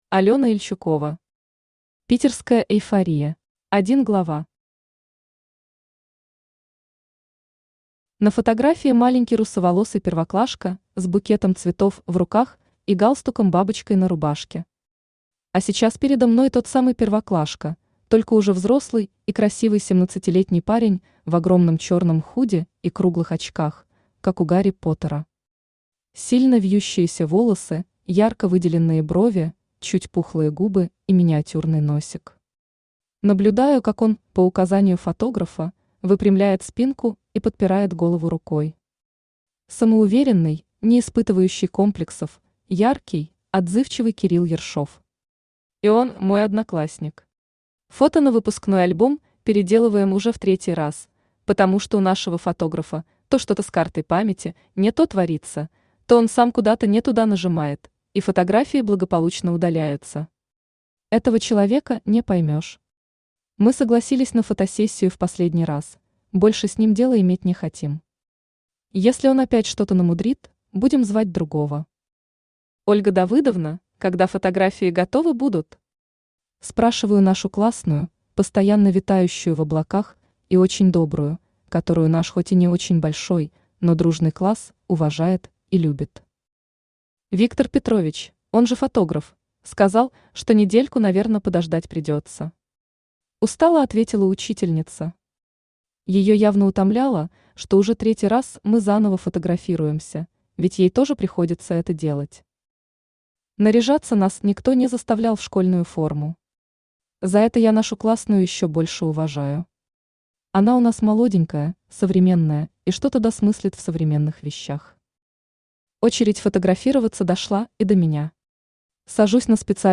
Аудиокнига Питерская эйфория | Библиотека аудиокниг
Читает аудиокнигу Авточтец ЛитРес.